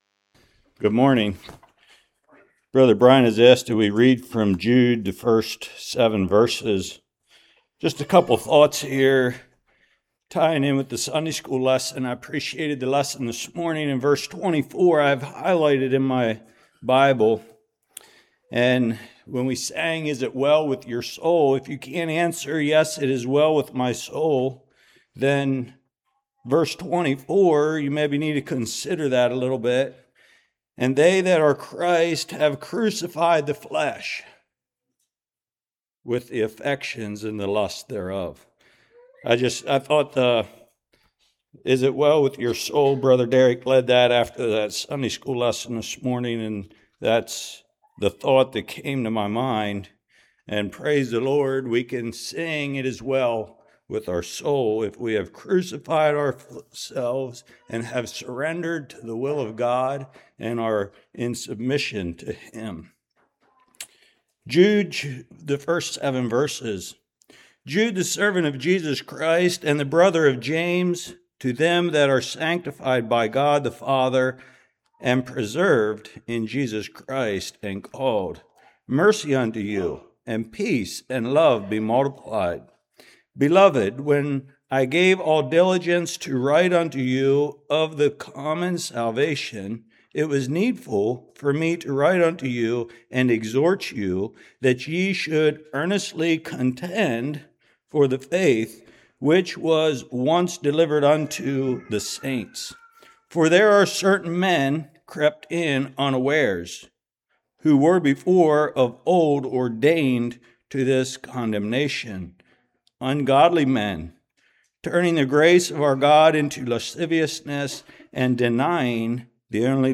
Jude 1-7 Service Type: Morning Titus 2:11-13 What Grace is.